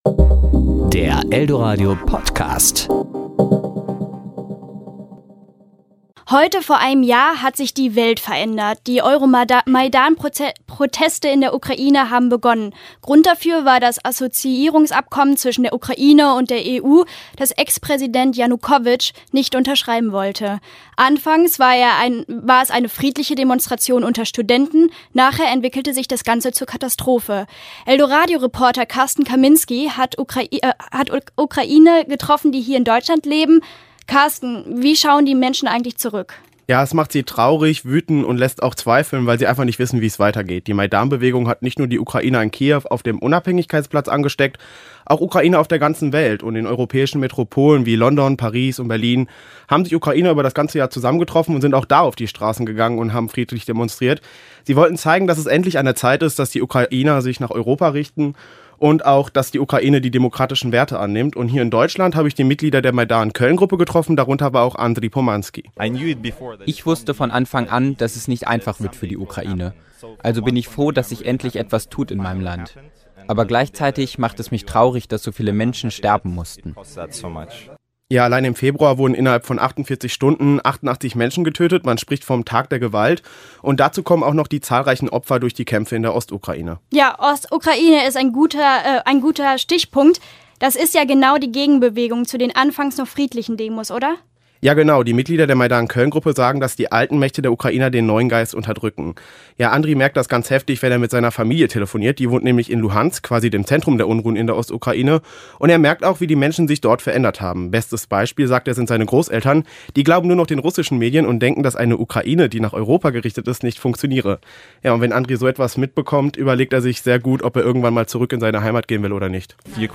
Serie: Wort